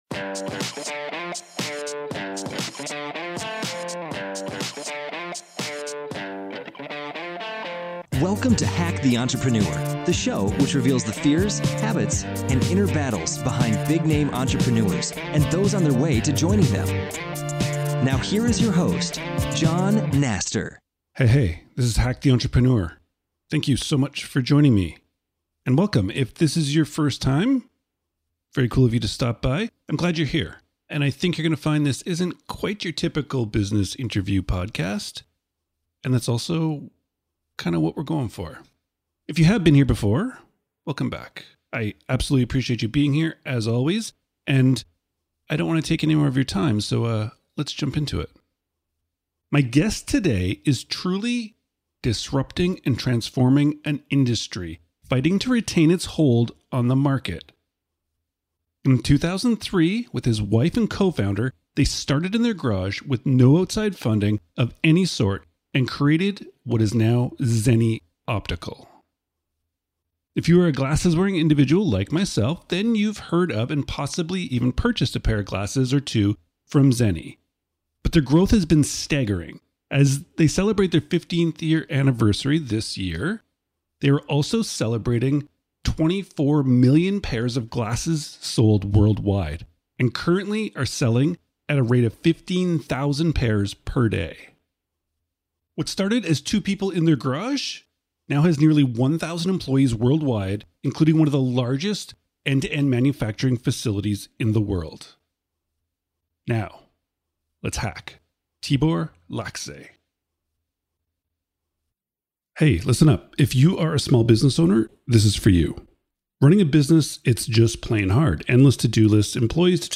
In this conversation we discuss: